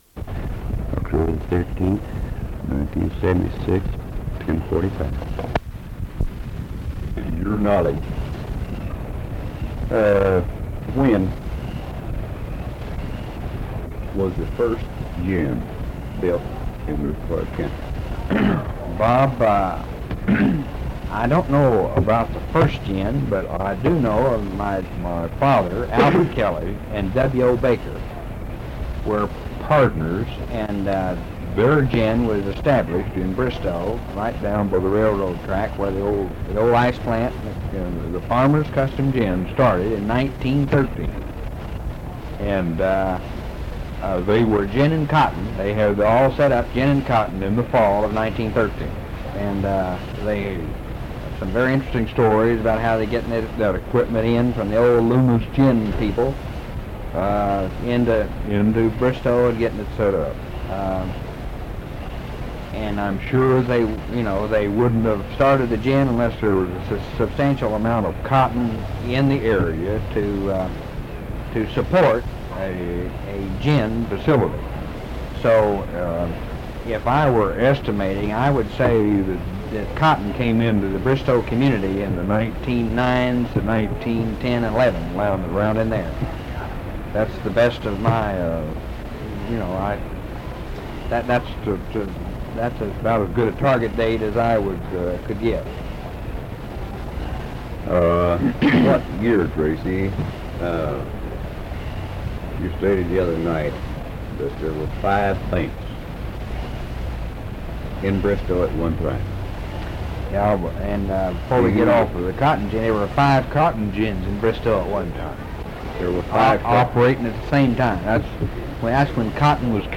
Bristow Historical Society - Oral History Archive | Oil Drilling - The Early Years